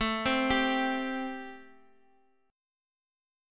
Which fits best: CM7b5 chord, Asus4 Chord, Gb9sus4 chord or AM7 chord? AM7 chord